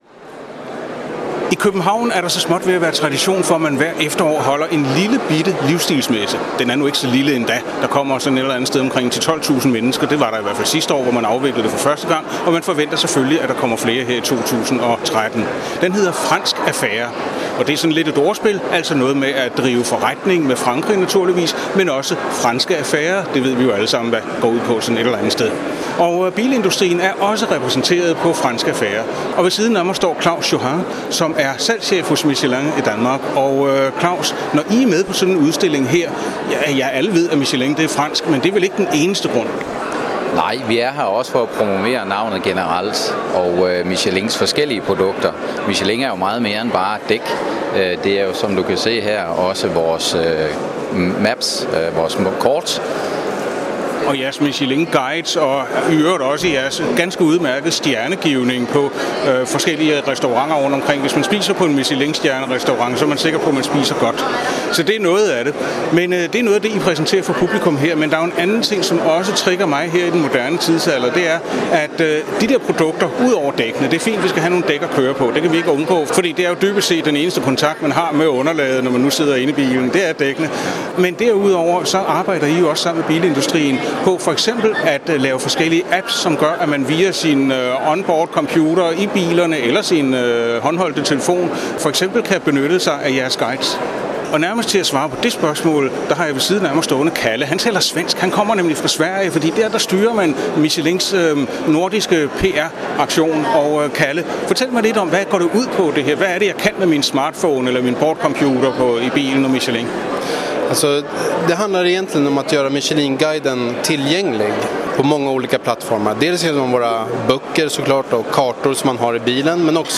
Fransk Affære i København:
Interview